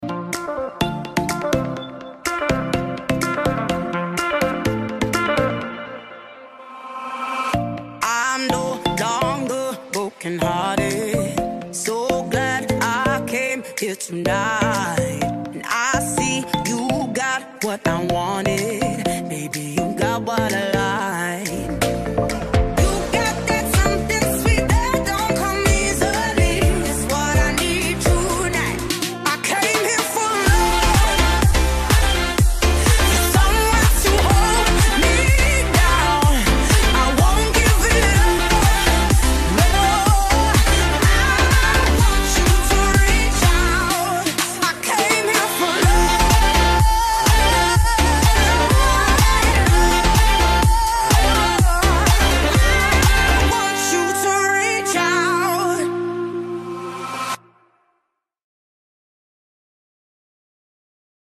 Open format, can play any genres and style.